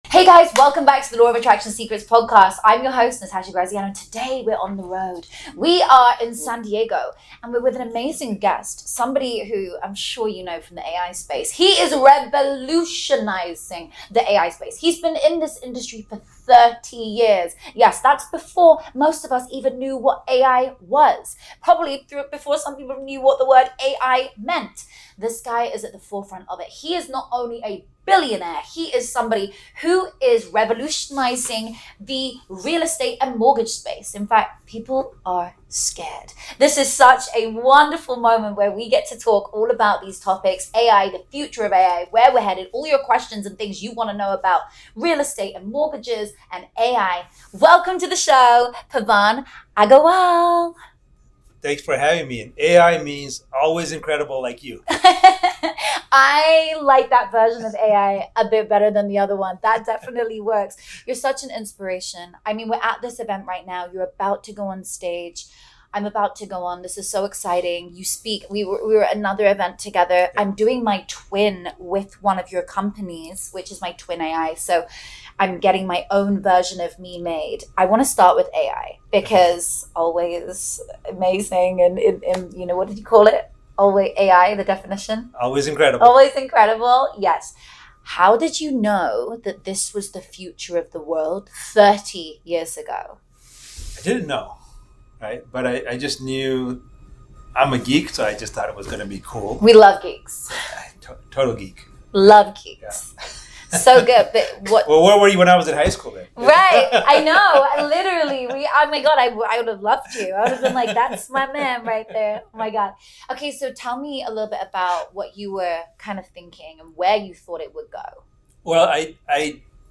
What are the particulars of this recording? We’re live from San Diego 🎤 and today’s guest is someone who’s been shaping the AI world for over 30 years — long before most of us even knew what “AI” meant!